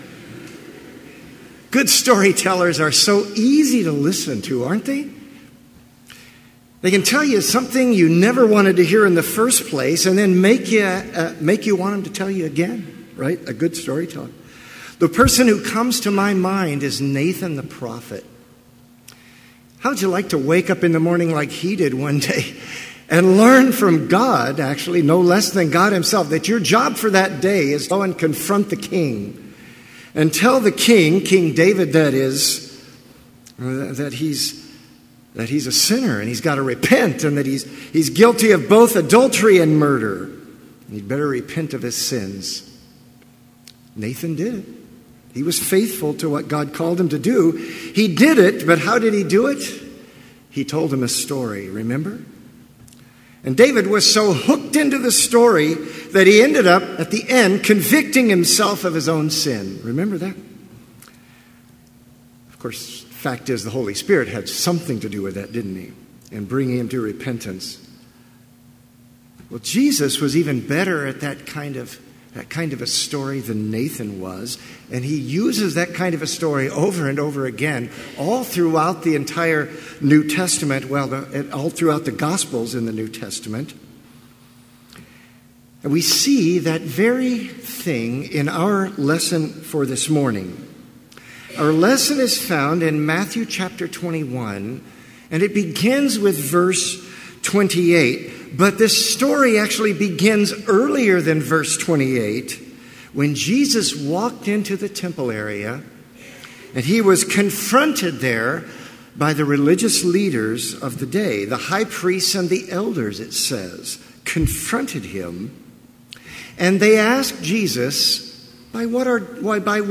Sermon audio for Chapel - August 29, 2017